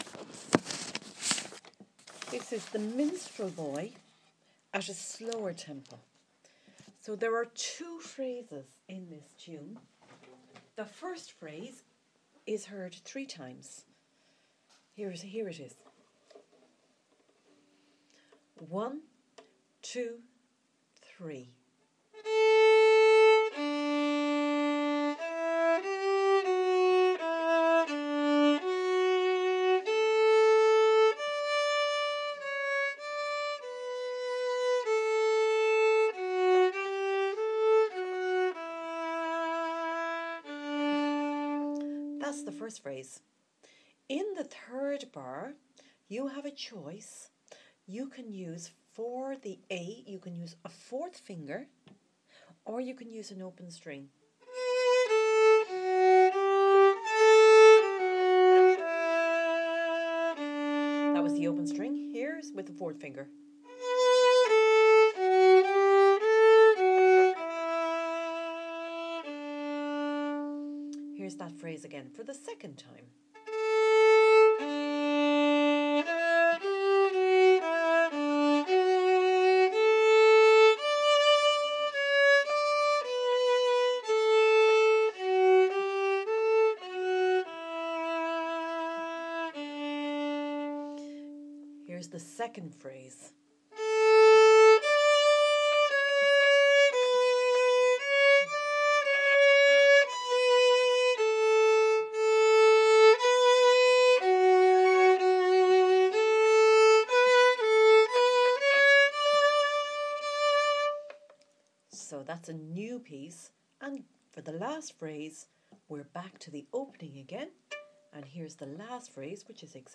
The Minstrel Boy slow